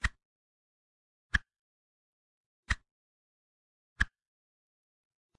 一组基于生态框架的日常声音" Single Plate Clash
描述：撞板
声道立体声